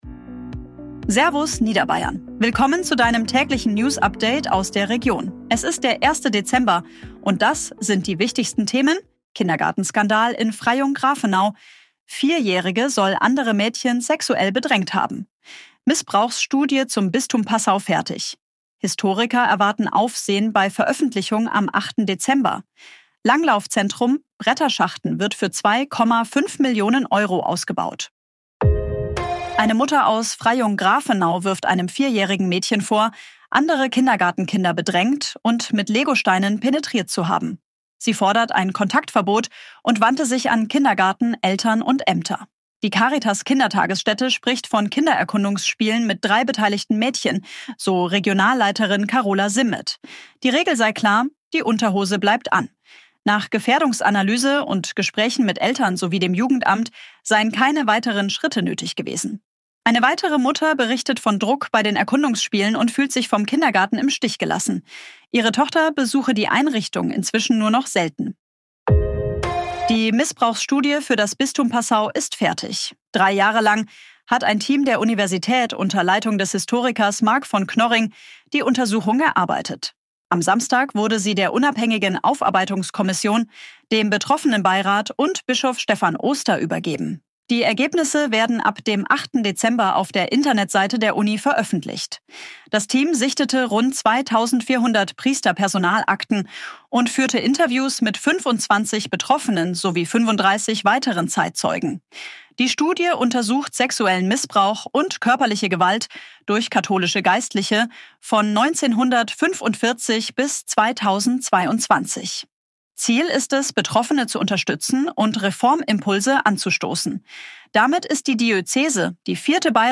Dein tägliches News-Update